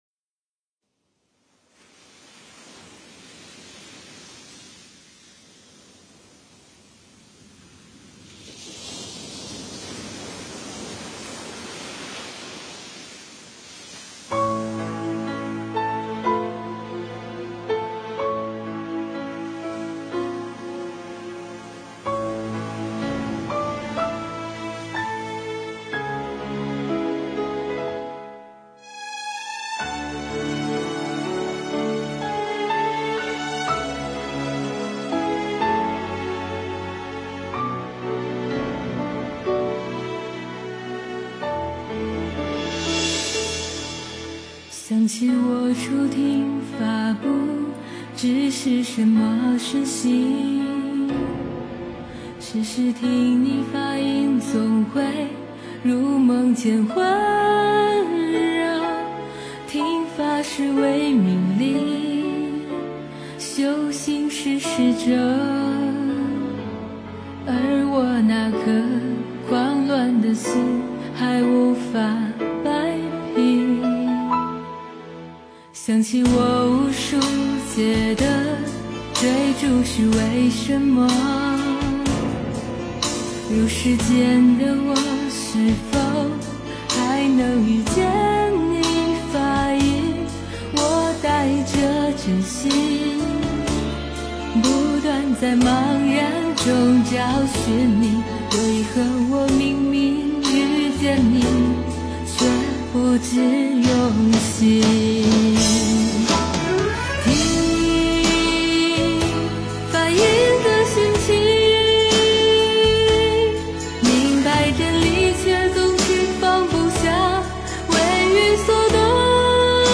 听法 诵经 听法--佛教音乐 点我： 标签: 佛音 诵经 佛教音乐 返回列表 上一篇： 随愿 下一篇： 同心同德 相关文章 34.禅观的世界--佚名 34.禅观的世界--佚名...